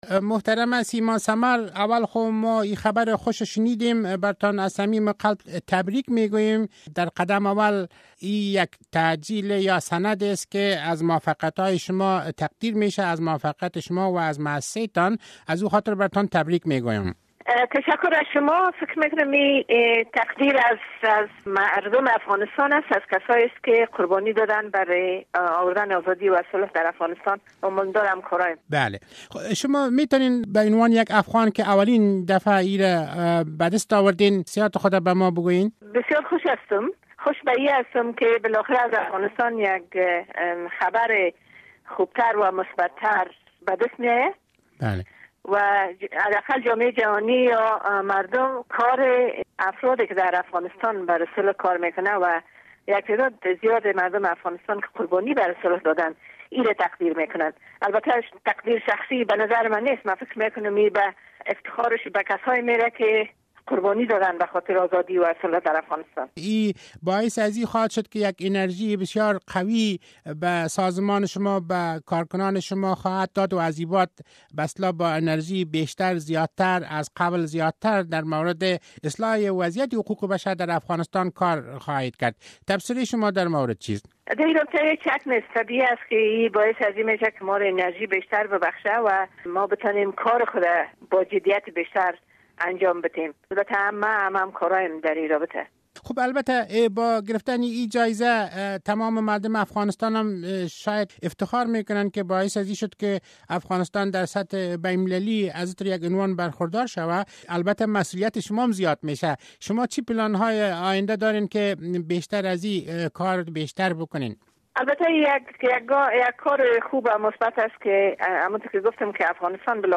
مصاحبه با سیما ثمر برندهء جایزهء نوبل